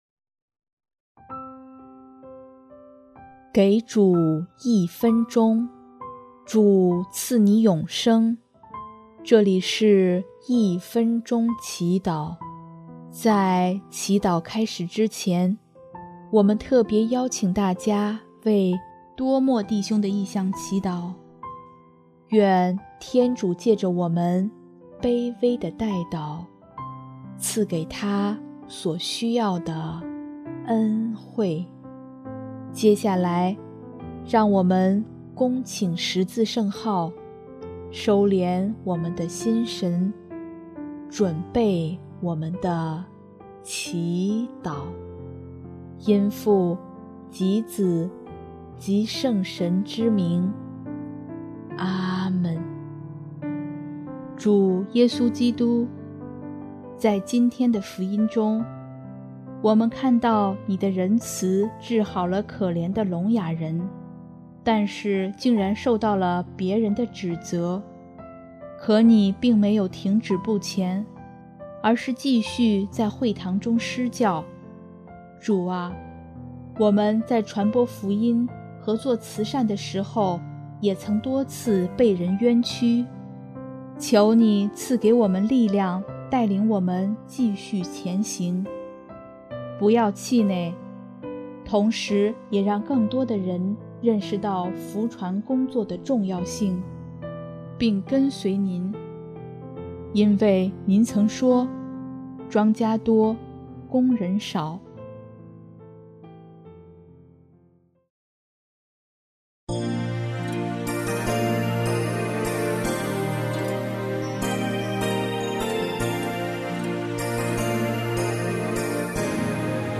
【一分钟祈祷】|7月8日 庄稼多，工人少……
音乐： 主日赞歌《庄稼已成熟》